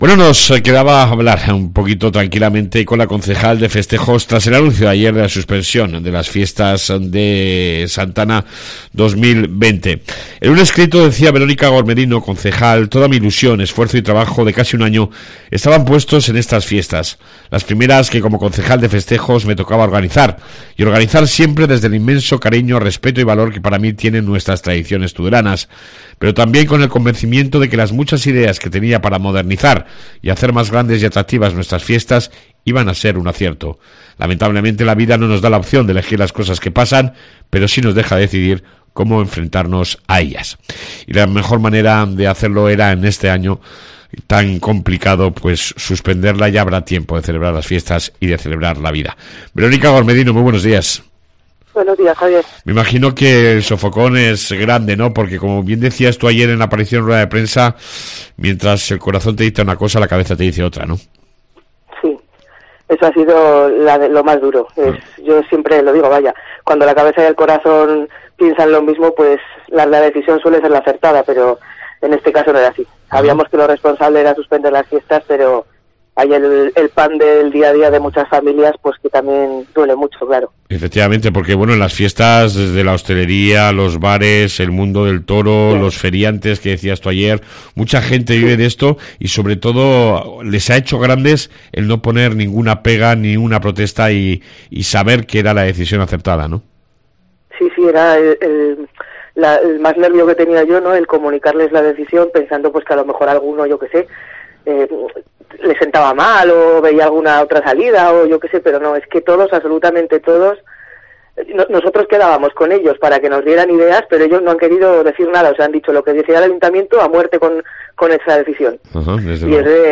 AUDIO: Entrevista con la Concejal de Festejos Veronica Gormedino